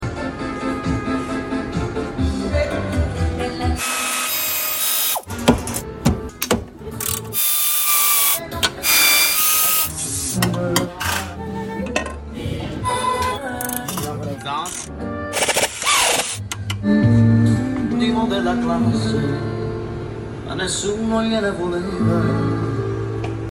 ASMR For Car Guys → Sound Effects Free Download